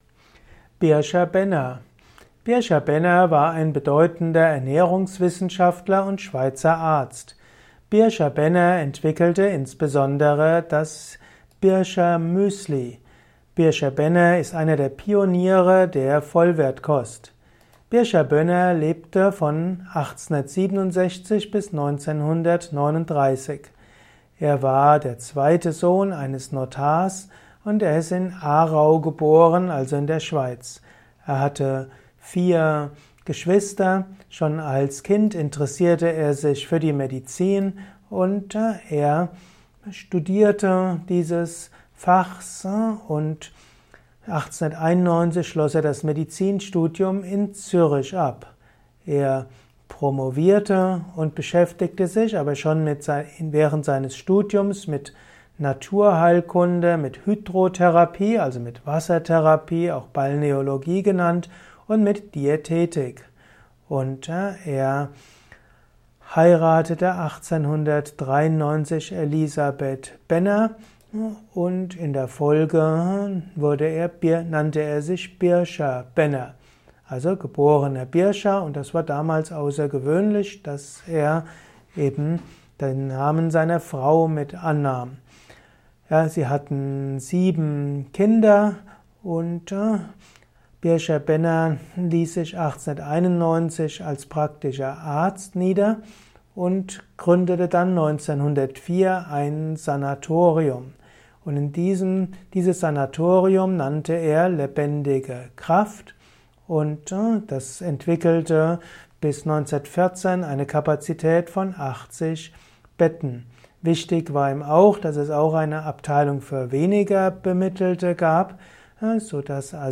Kompakte Informationen zum Thema Bircher-Benner in diesem Kurzvortrag